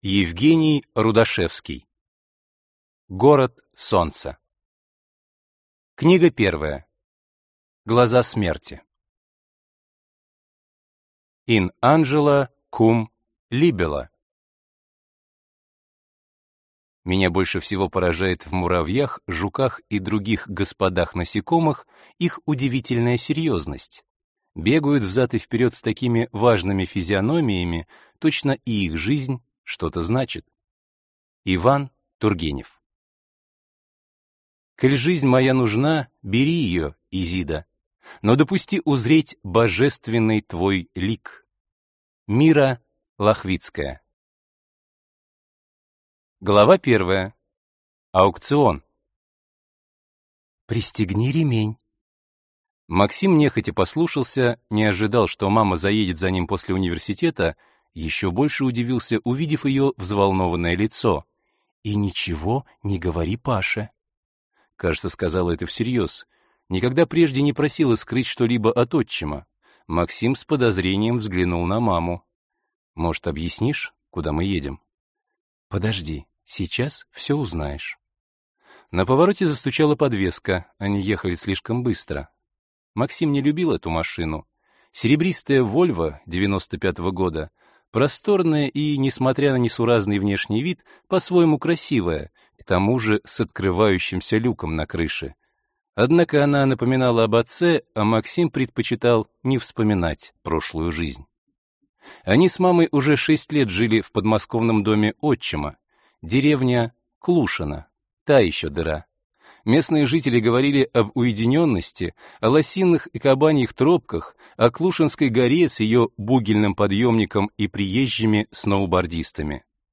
Аудиокнига Город Солнца. Глаза смерти | Библиотека аудиокниг